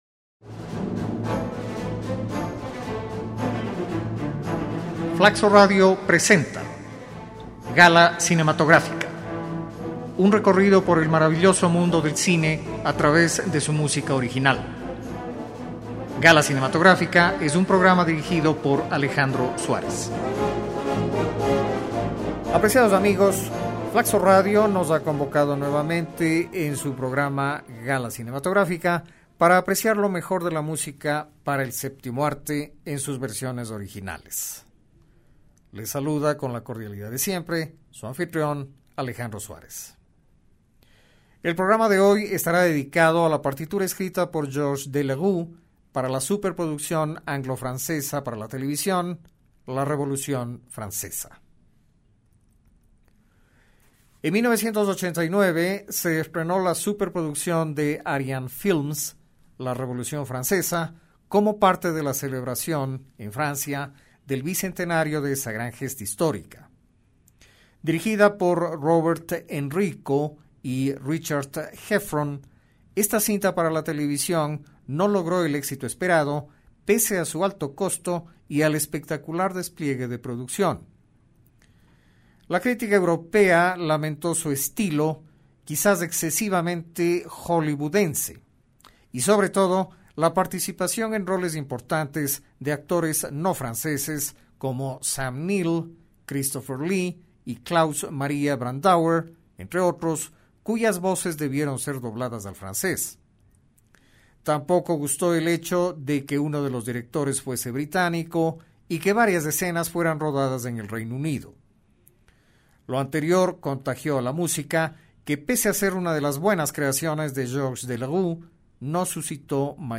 Su narración musical es muy bien lograda y convincente.